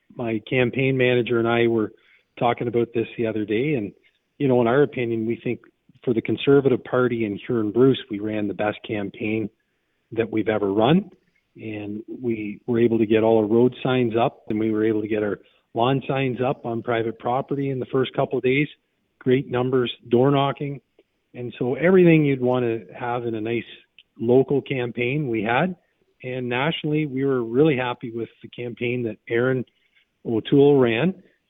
Tonight is election night, and as we wait for the polls to close we caught up with Incumbent Conservative Ben Lobb.